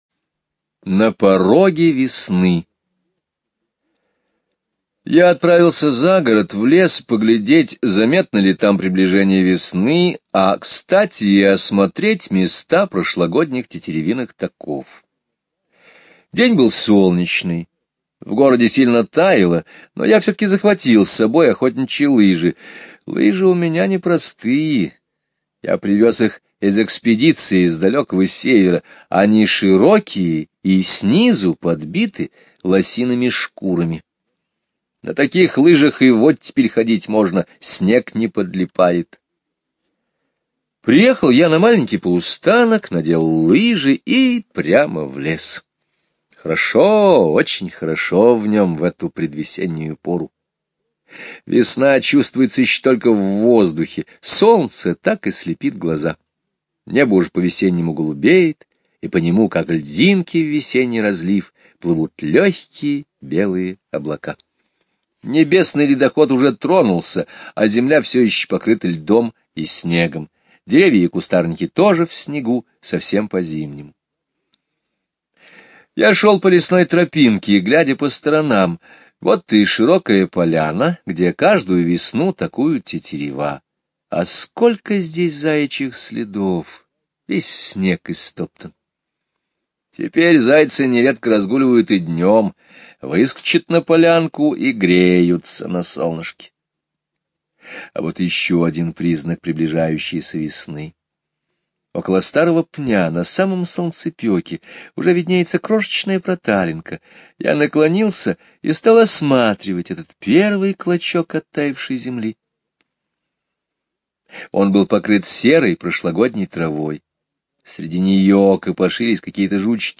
Слушайте аудио рассказ "На пороге весны" Скребицкого Г. онлайн на сайте Мишкины книжки. Рассказ о том, как природа не спеша просыпается после зимы. Автор пошел на лыжах в лес и встретил там лося, сбрасывающего рога. skip_previous play_arrow pause skip_next ...